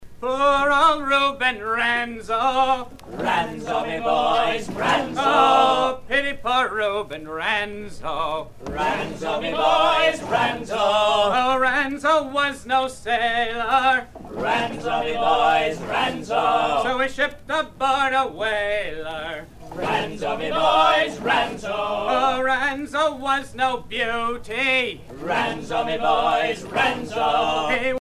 maritimes
Pièce musicale éditée